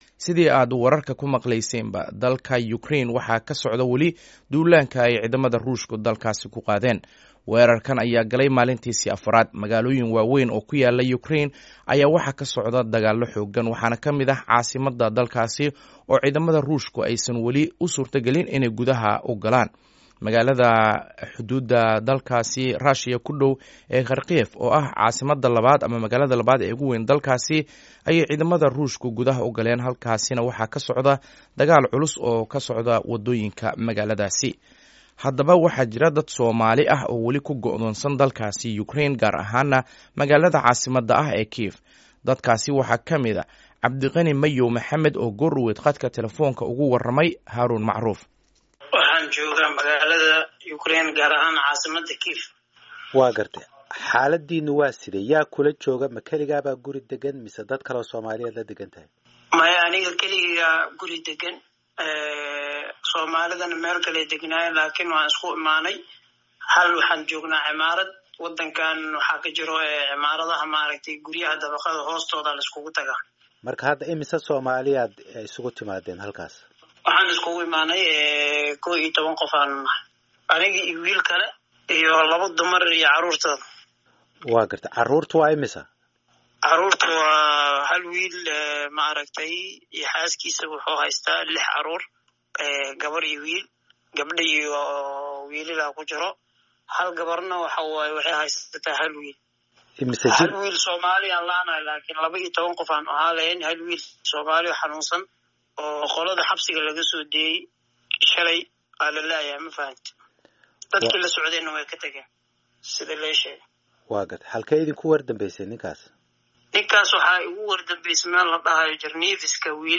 Wareysi: Soomaali ku Go'doonsan Ukraine